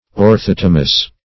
Search Result for " orthotomous" : The Collaborative International Dictionary of English v.0.48: Orthotomous \Or*thot"o*mous\, a. (Crystallog.) Having two cleavages at right angles with one another.